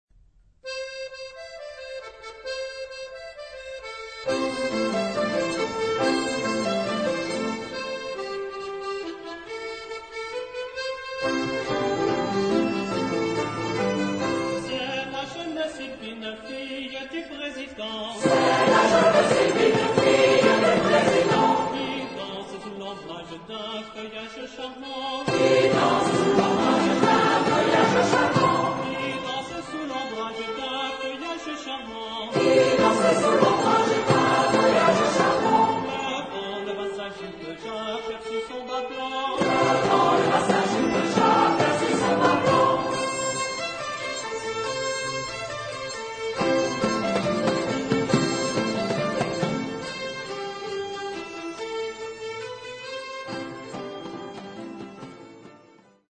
Mood of the piece: joyous ; roguish
Type of Choir: SATB  (4 mixed voices )
Soloist(s): Ténor (1)  (1 soloist(s))
Tonality: C major